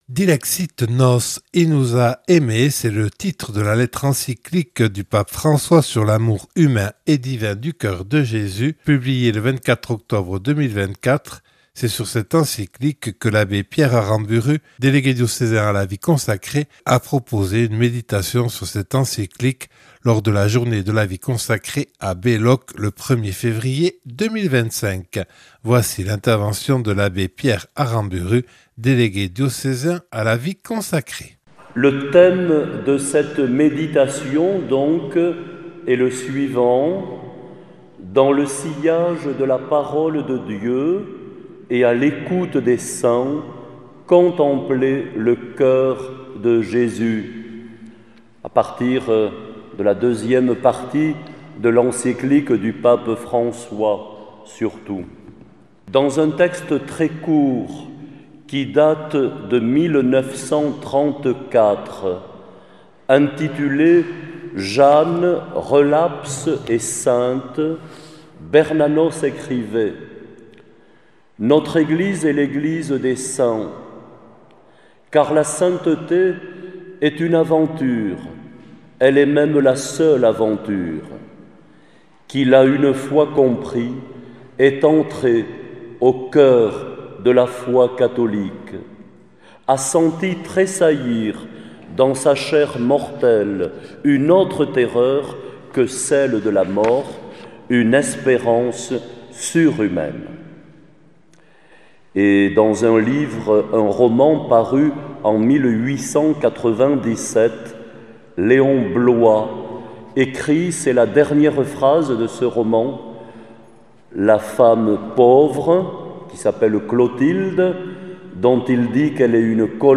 (Enregistré le 01/02/2025 lors de la Journée diocésaine de la vie consacrée à Belloc).